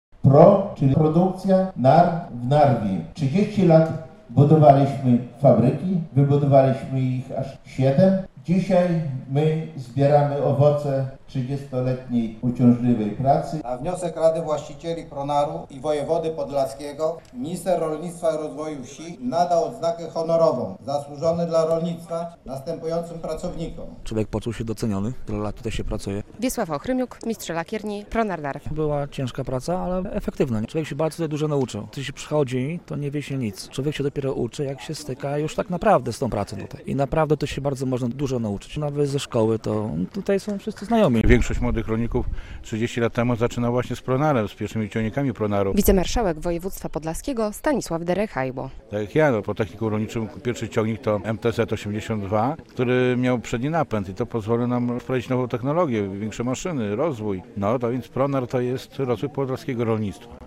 Pronar świętuje 30-lecie istnienia - relacja